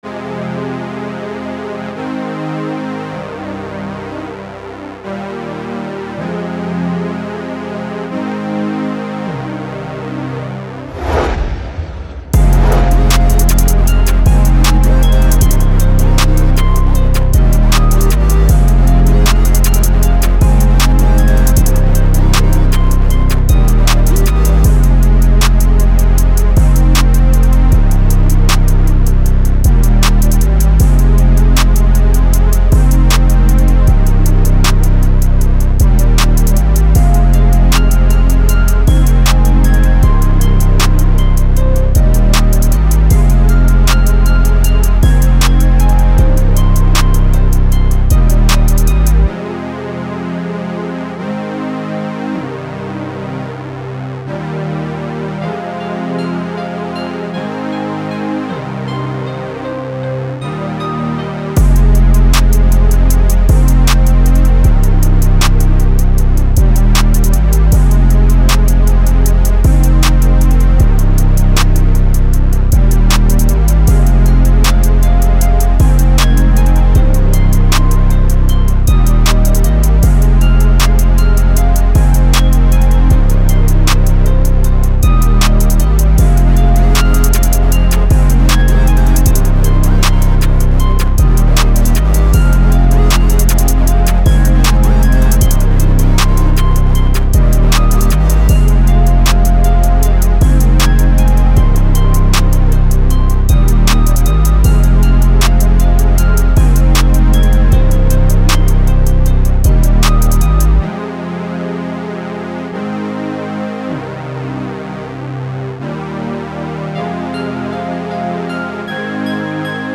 Cold, Dark, Energetic, Sexy
Acoustic Guitar, Drum, Heavy Bass, Piano, Strings